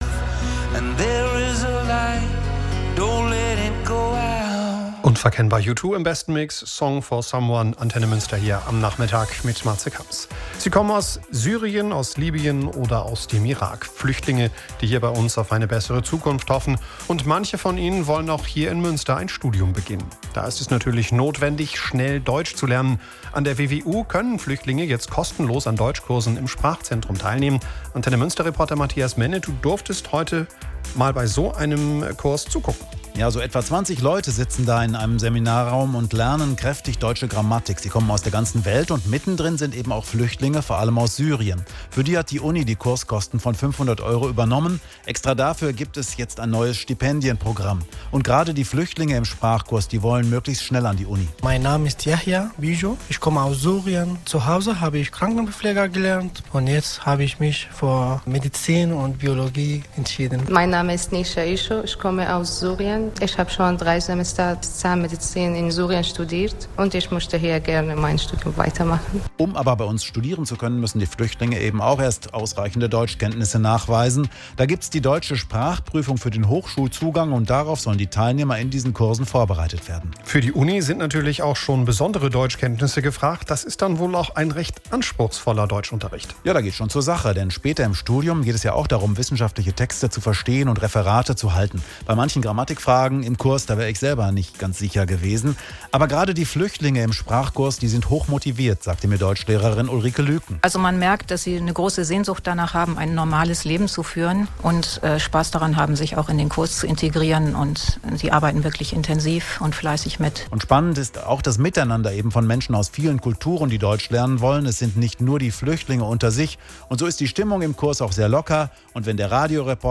Radio-Beitrag von Antenne Münster